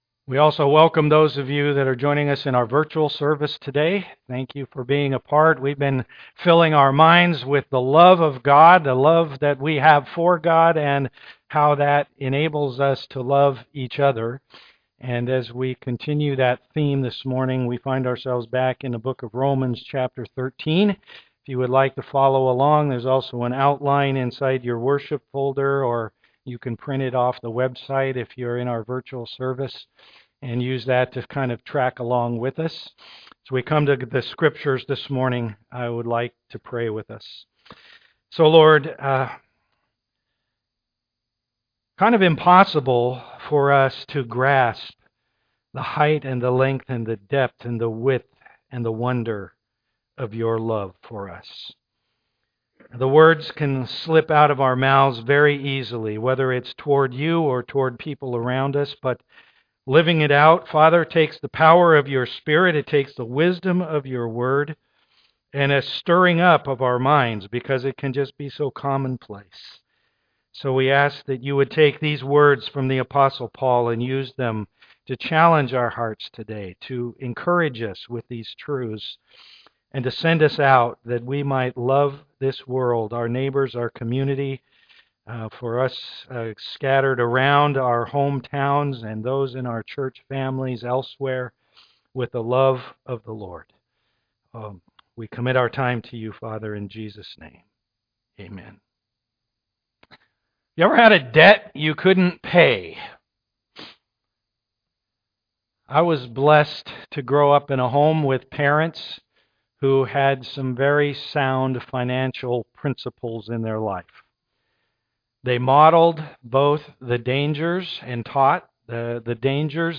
Romans 13:8-14 Service Type: am worship Everyone wants to talk about love.